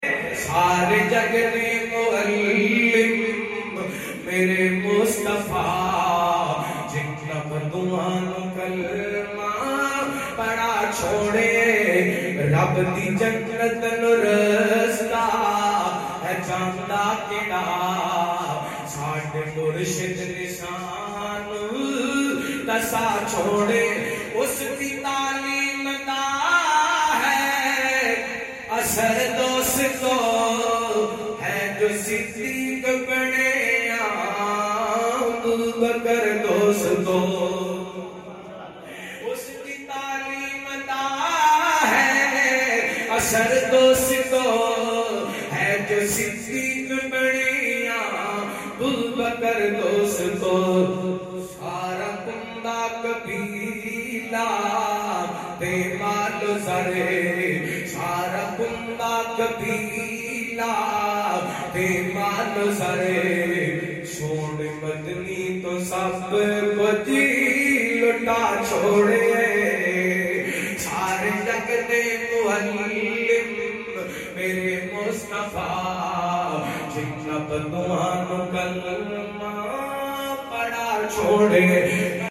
naat at Lahore